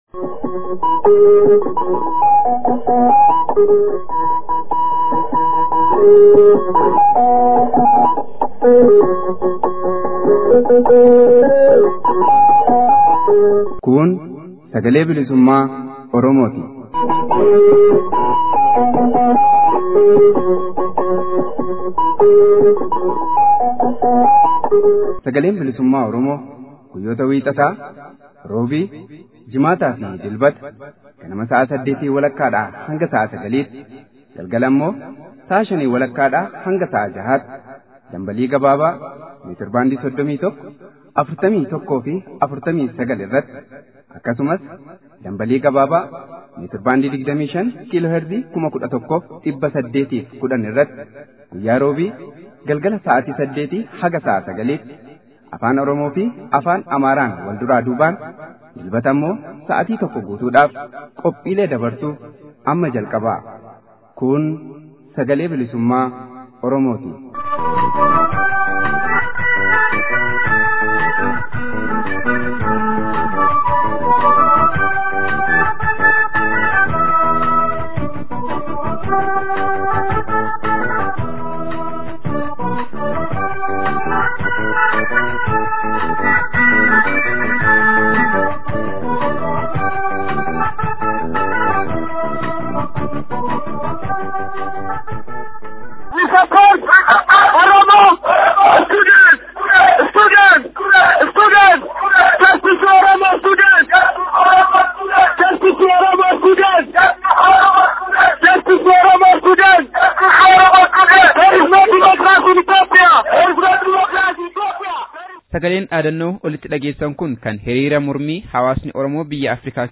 SBO Gurraandhala 21,2016. Oduu, Gabaasa FXG Oromiyaa, FXG Oromiyaa keessaa belbelaa jiruu irratti gaaffii fi deebii dargaggoo